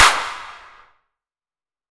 80-s-clap(1).wav